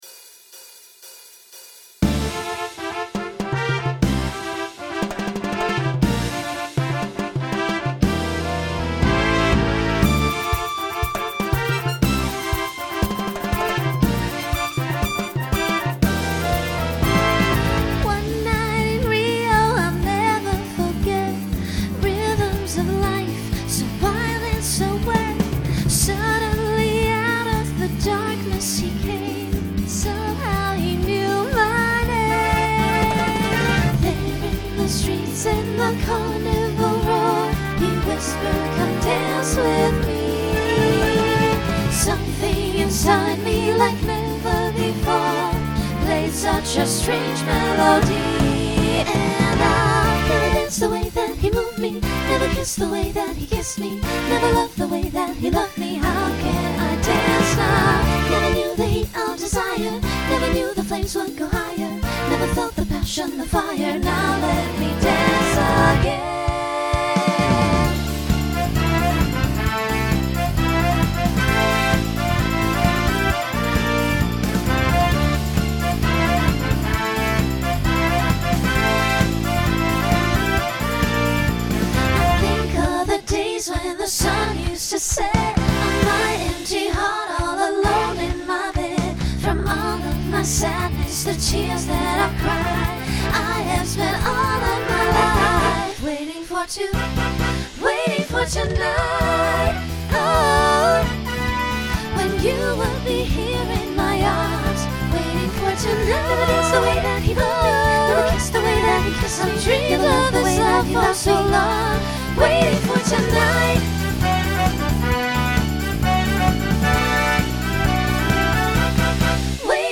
Genre Latin Instrumental combo
Transition Voicing SSA